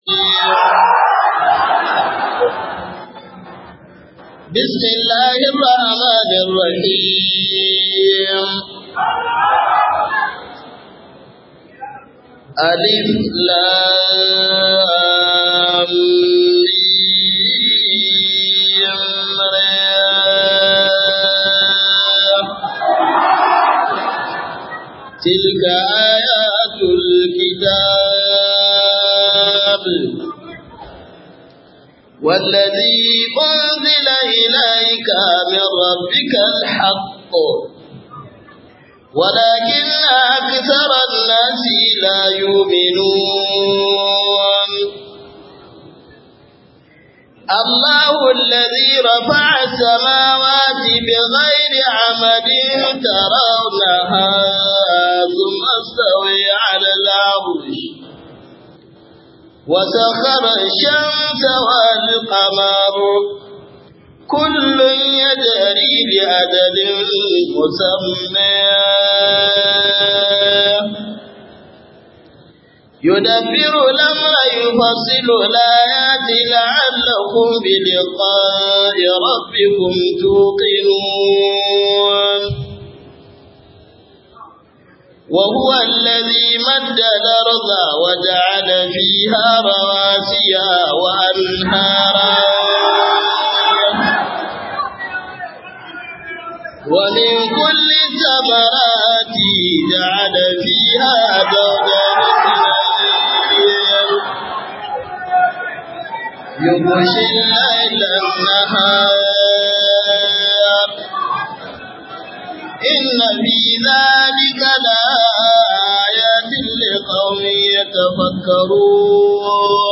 005 Wa'azin Walima Bauchi - Sheikh Kabir Gombe